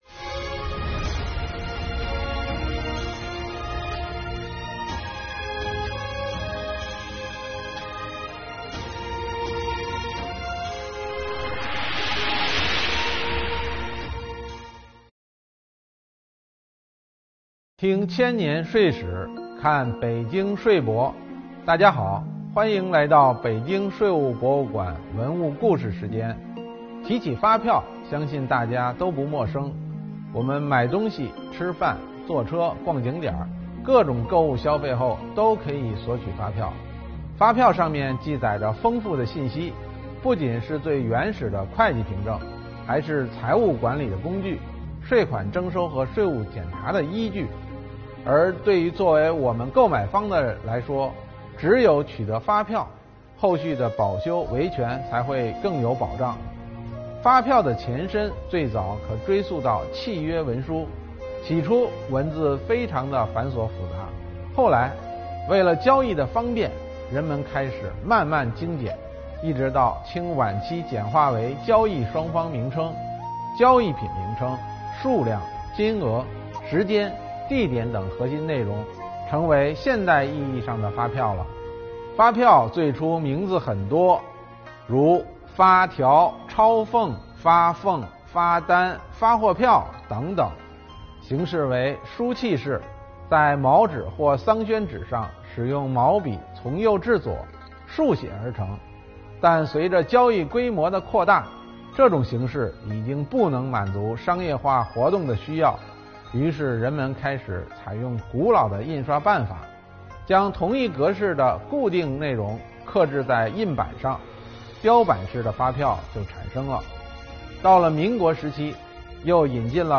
北京税务博物馆文物故事讲述人为大家讲述发票的前世今生↓